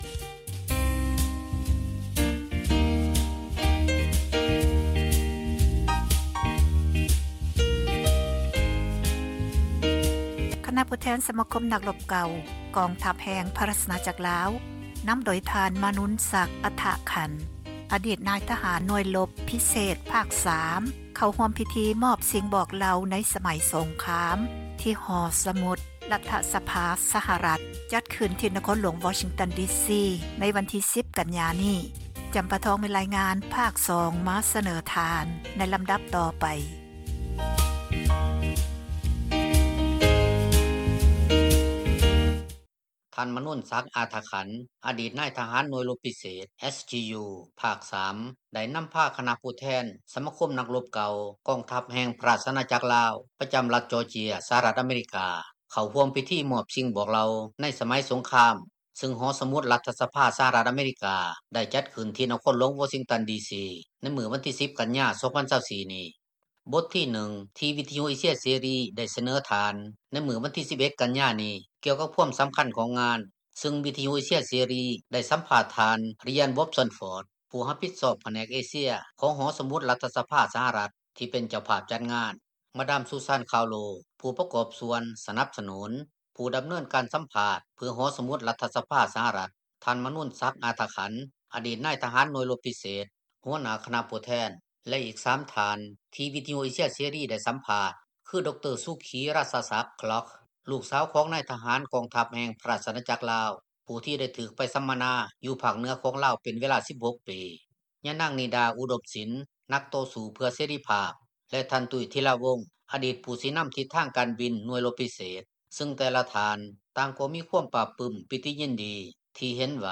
ສຳພາດ ຜູ້ແທນສະມາຄົມ ນັກລົບເກົ່າ